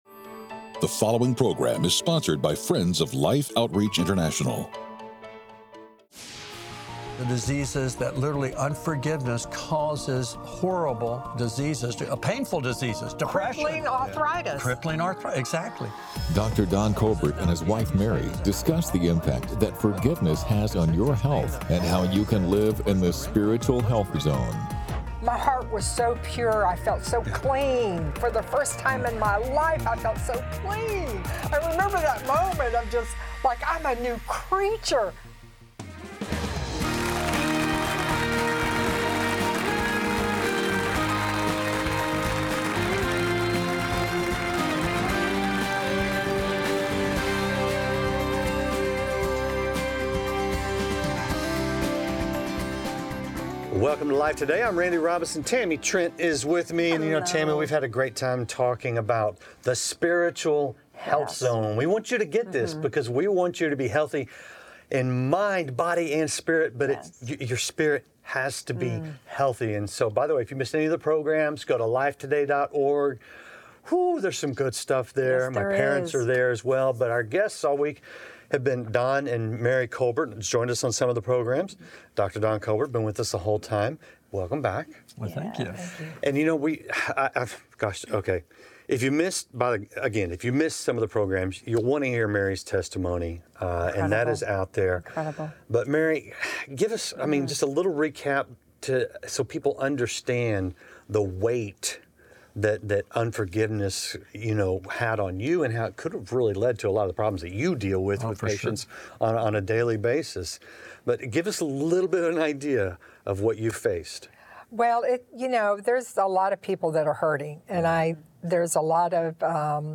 A medical doctor explains the physical impacts of forgiveness as his wife shares the life-changing freedom she found by forgiving the one who hurt her the most.